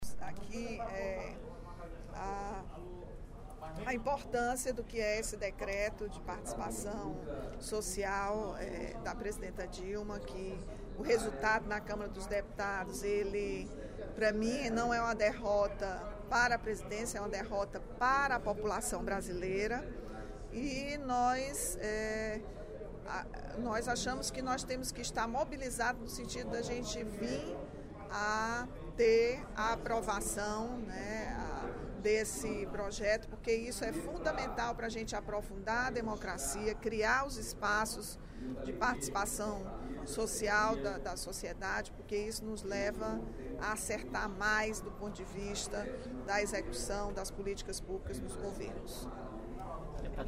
A deputada Rachel Marques (PT), líder do PT na Assembleia Legislativa, fez pronunciamento nesta terça-feira (11/11), durante o primeiro expediente da sessão plenária, para apresentar as resoluções aprovadas pela Executiva Nacional do Partido dos Trabalhadores, após as eleições.